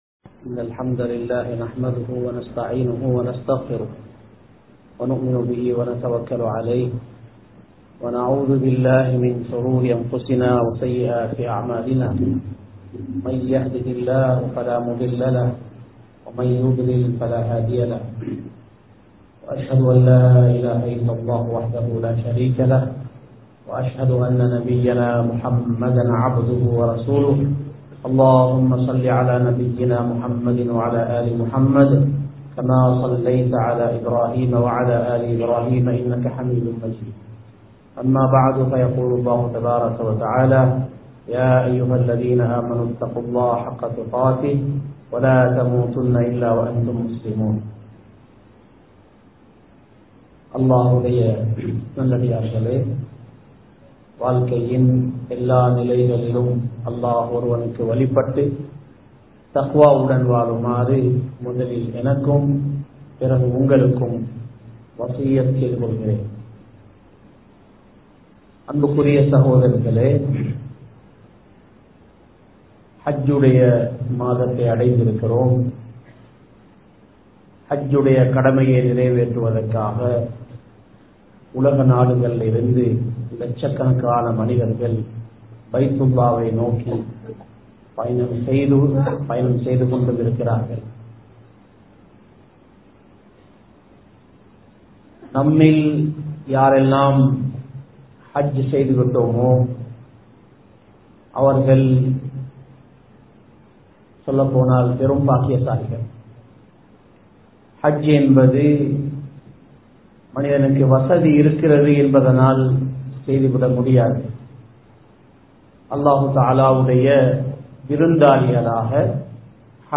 ஹஜ்ஜின் அவசியம் | Audio Bayans | All Ceylon Muslim Youth Community | Addalaichenai
Colombo 06, Kirulapana Thaqwa Jumua Masjith